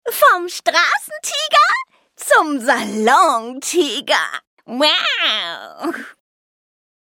Jeune, Amicale, Distinctive, Polyvalente, Douce
Elle peut parler en allemand neutre, mais aussi dans un dialecte nord-allemand.